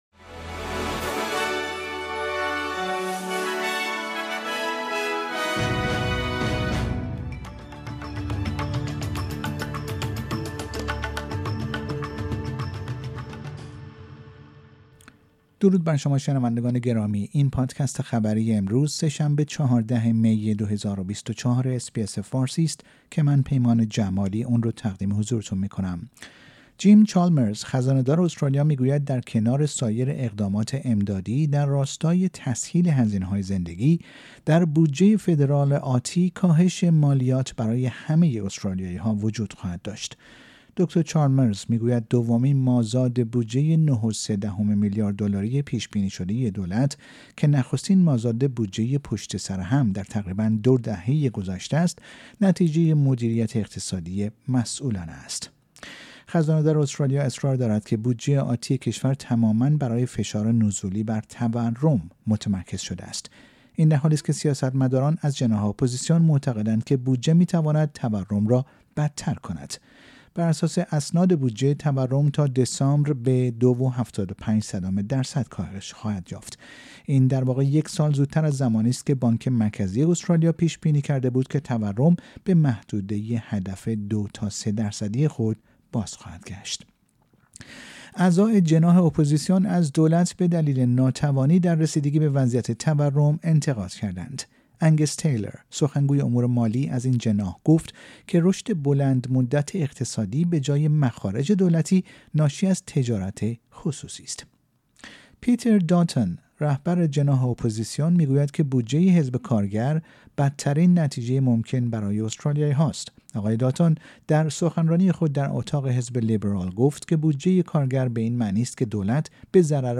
در این پادکست خبری مهمترین اخبار استرالیا، در روز سه شنبه ۱۴ مه ۲۰۲۴ ارائه شده است.